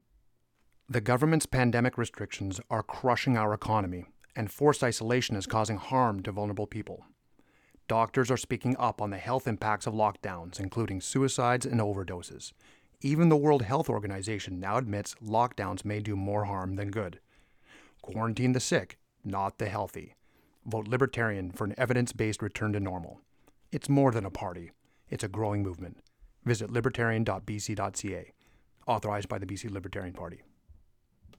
Is the Lockdown Causing More Harm than Good radio ad, run in Kelowna and White Rock: